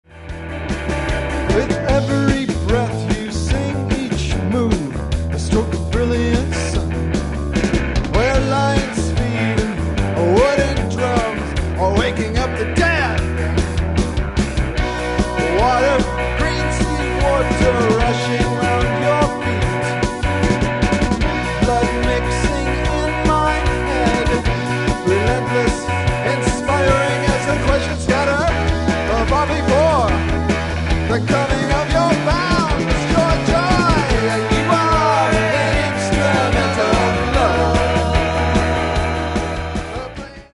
is the last studio recording I made with the band